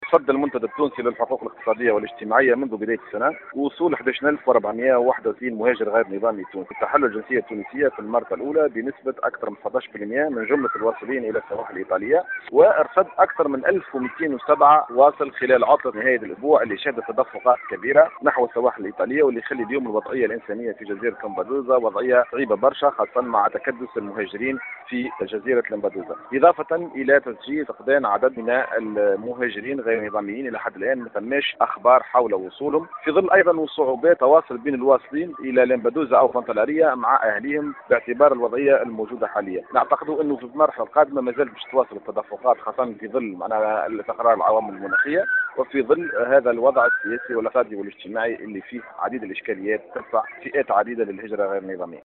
في تصريح ل” ام اف ام” عن وصول قرابة 11.460 مهاجر غير شرعي إلى السواحل الإيطالية منذ بداية سنة 2022. وأبرز أن هذا الرقم ليس صادما بالنسبة للمنتدى التونسي للحقوق الاقتصادية والاجتماعية ، قائلا إن الجنسية التونسية المرتبة …